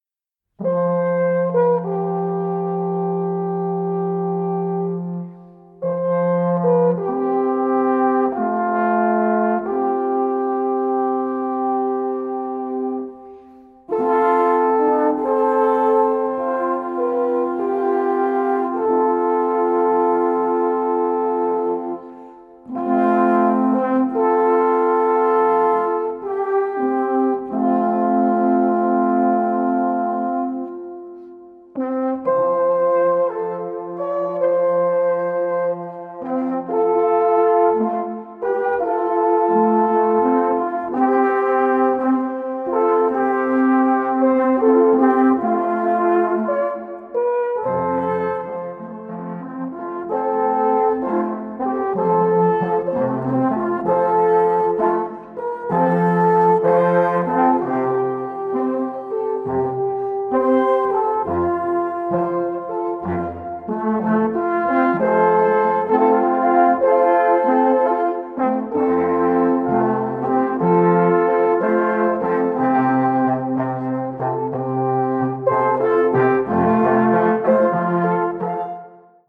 Alphorn-Trio